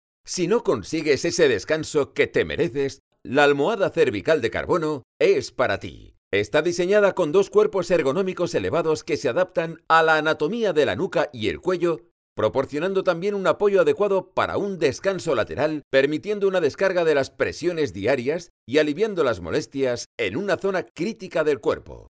The studio features expert acoustic design and the latest in digital audio technology like : Microphones:  Kahayan 4k7 . Neumann TLM 170. Tube Tech equalizer, Apogee Symphony...  Quality and the better sound.